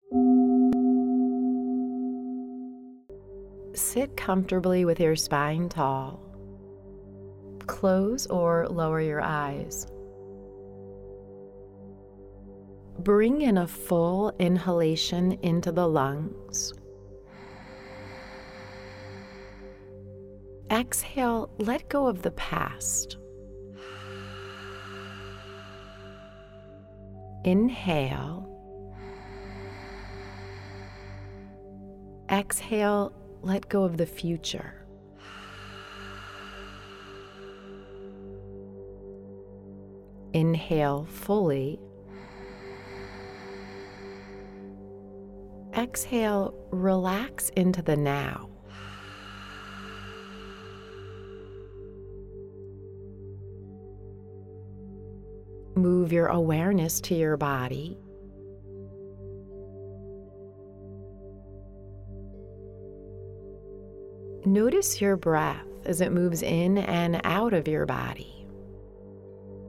Audio Meditation